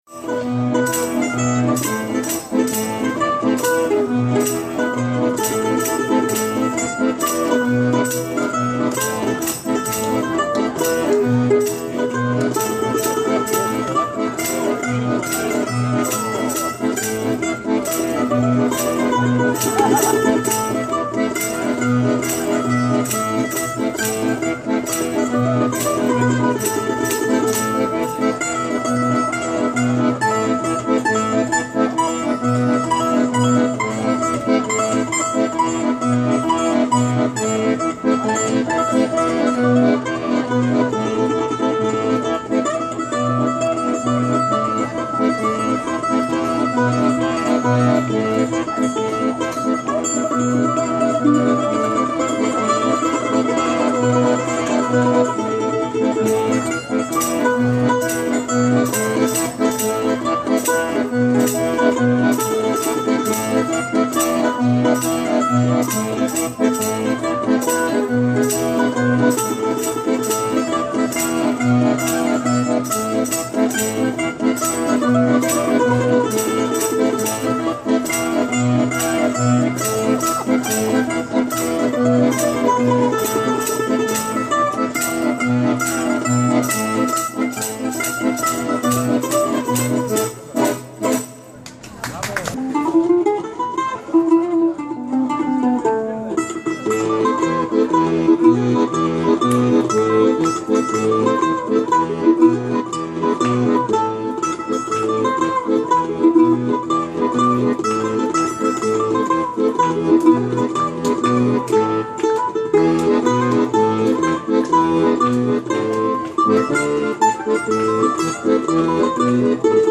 Italienisch-Deutsche Live-Musik Mandoline Akkordeon Tenorgesang Erfahrene Fest- und Hochzeitsmusiker
• Unplugged
• Akkordeon
• Polka (Tarantella)
tarantellee7.mp3